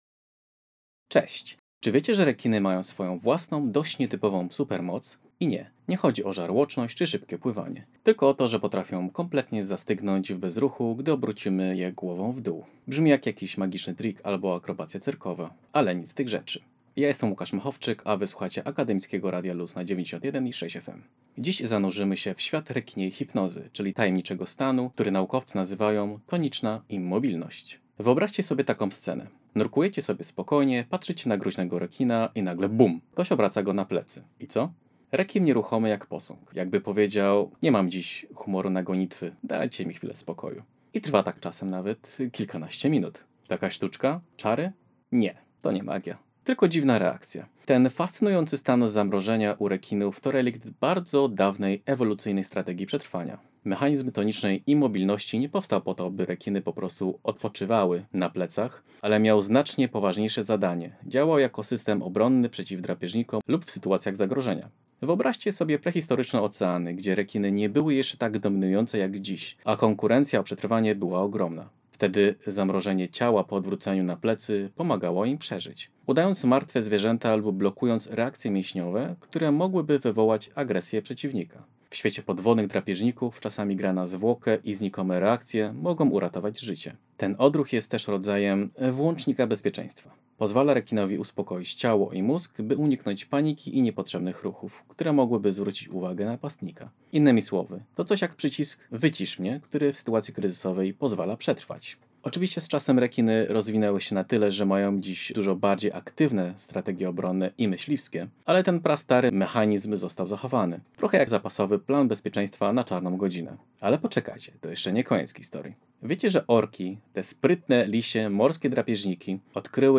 rekiny_manekiny_felieton.mp3